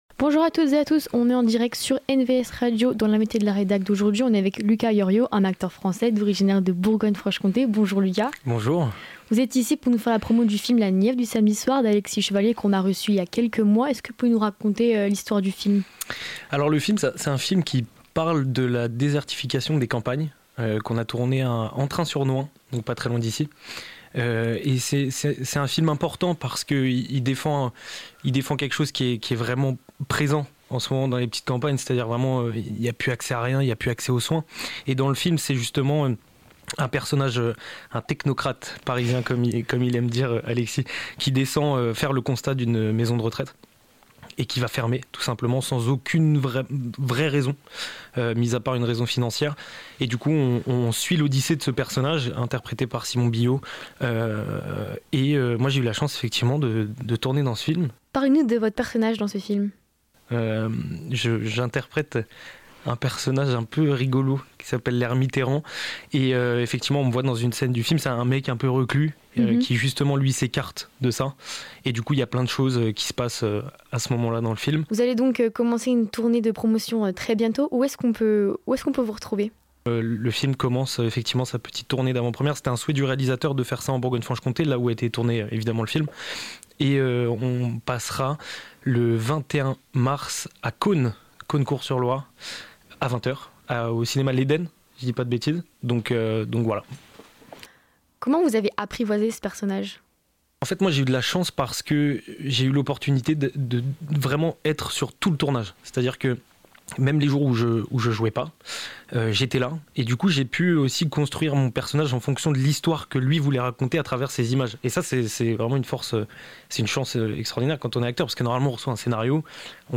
Au cours de cette interview, il partage aussi son parcours, ses projets et ses ressentis.